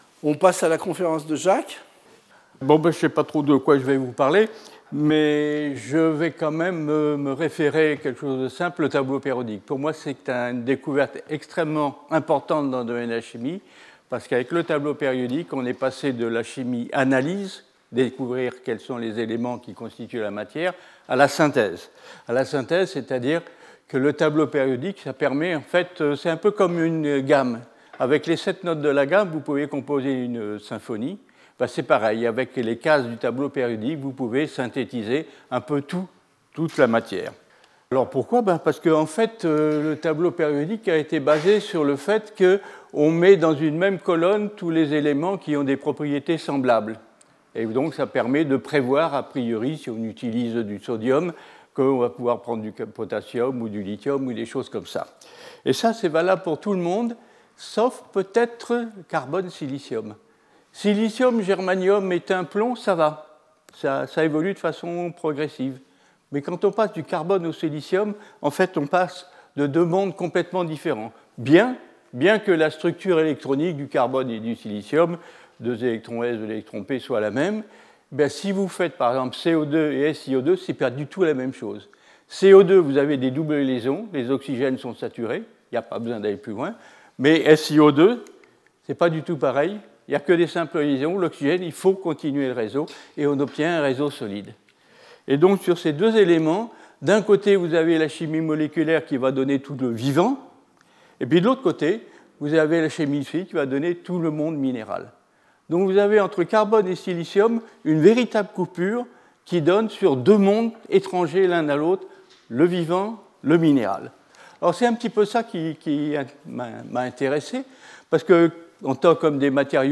Jacques Livage Professeur du Collège de France
Séminaire